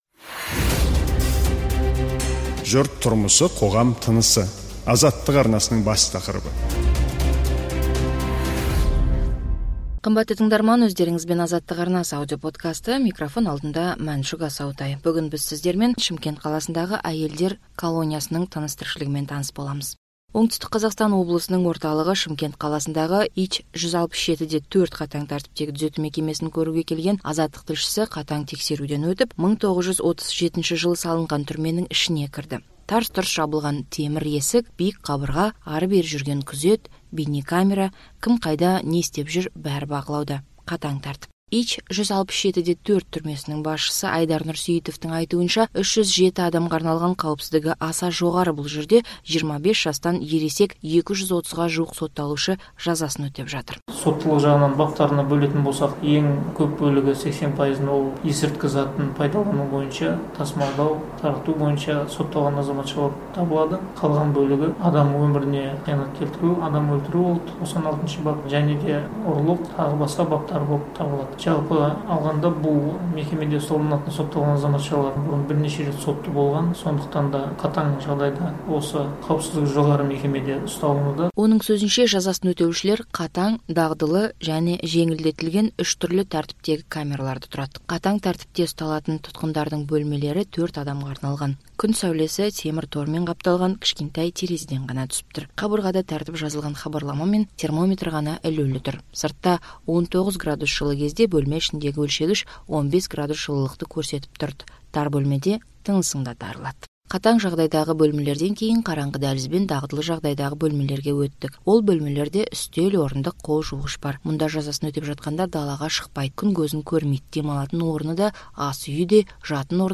Азаттық тілшісінің Шымкент түрмесінен репортажы.